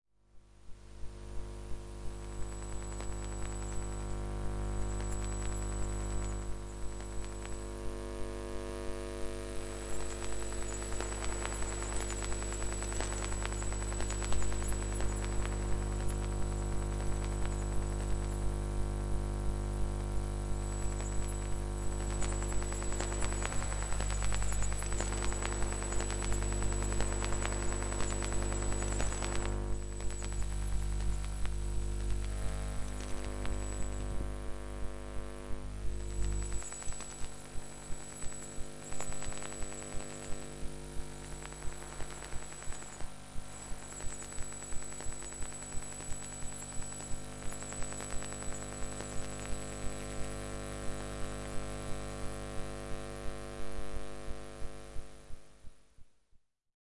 电磁场 " 调制解调器
描述：使用Zoom H1和电磁拾音器录制